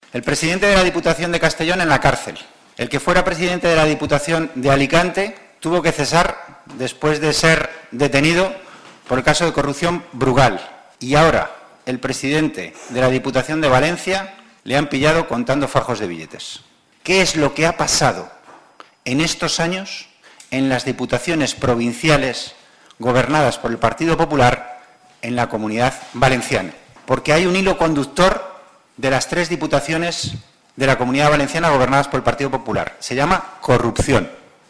Fragmento de las declaraciones de Antonio Hernando sobre Alfonso Rus, presidente de la Diputación de Valencia 5/05/2015